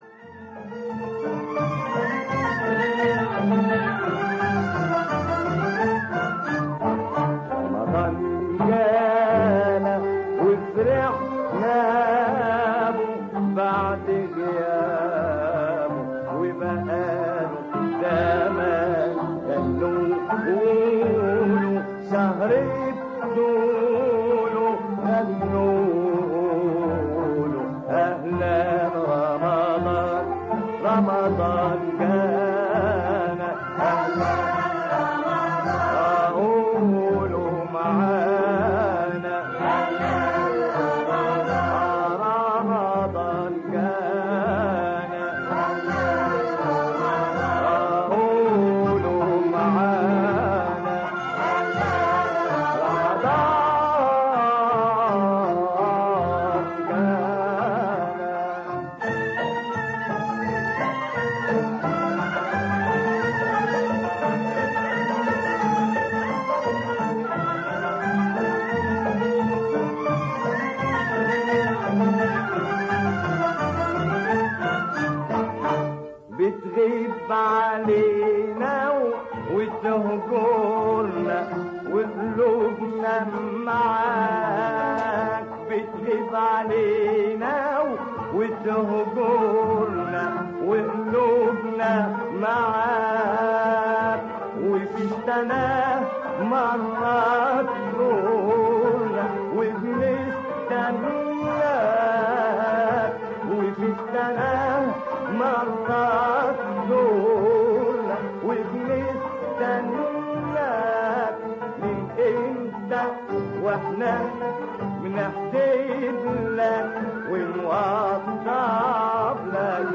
في هذه الفقرة نسلط الضوء على اهم هذه العادات و نستمع الى باقة من الاغاني الرمضانية المميزة القديمة و الحديثة.